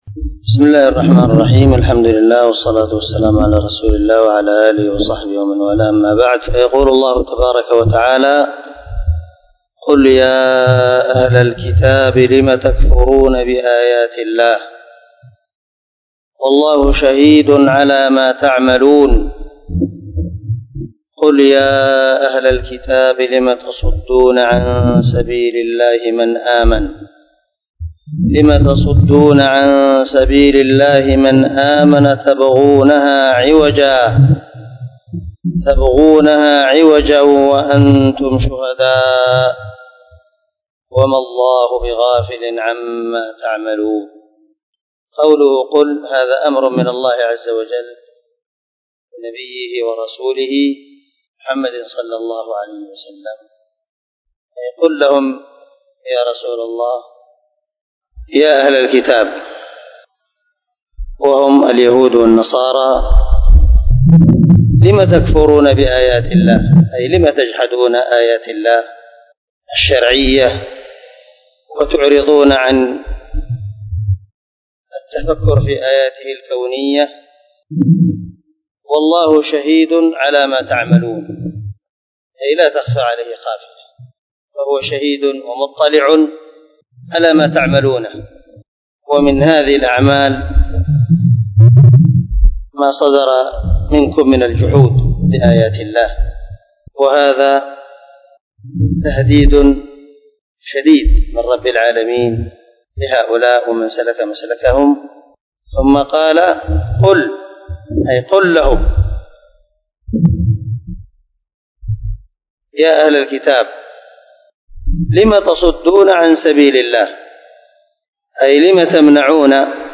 عنوان الدرس:
✒ دار الحديث- المَحاوِلة- الصبيحة.